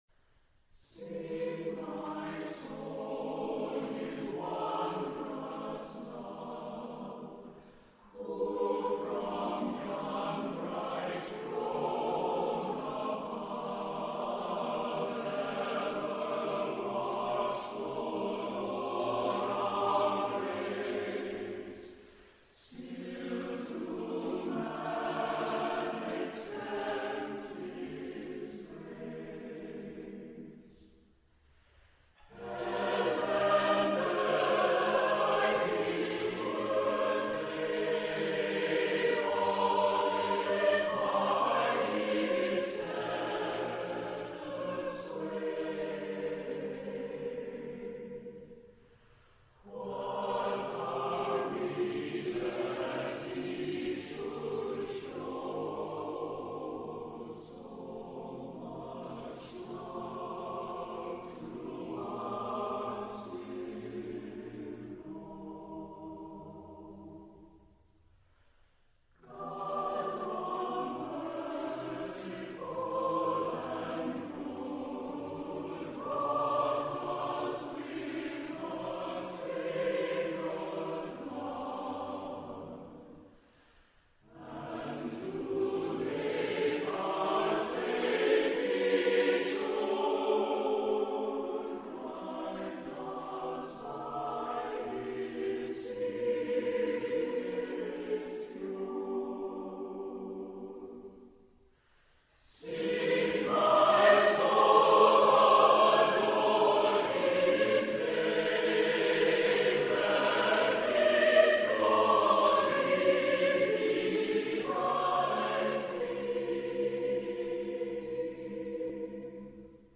Genre-Style-Forme : Sacré ; Hymne (sacré) ; Anthem
Type de choeur : SATB  (4 voix mixtes )
Tonalité : mi majeur